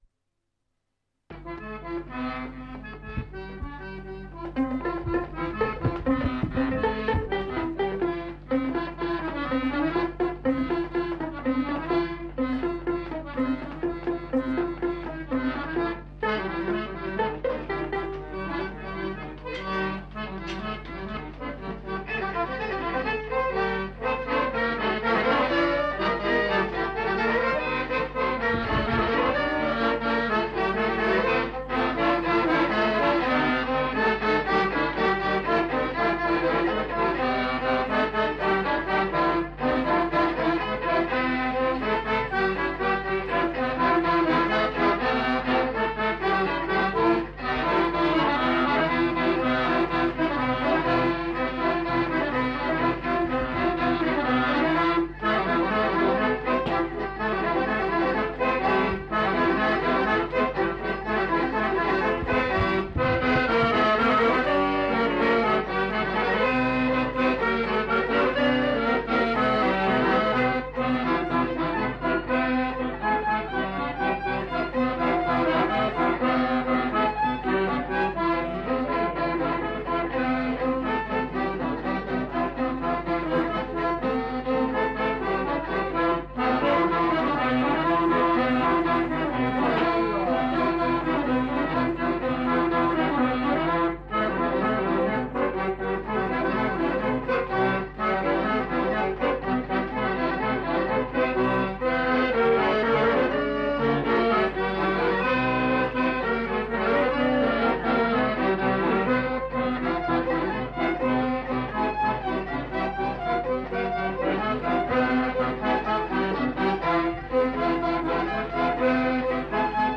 Répertoire de danses des Petites-Landes interprété au violon et à l'accordéon chromatique
Rondeau